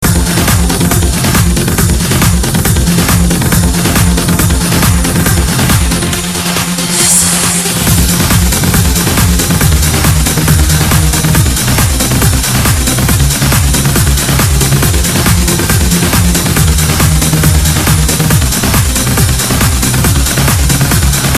apply advanced Voice filters & effects samples